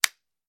Звук хлопанья хвостом фугу по разделочной доске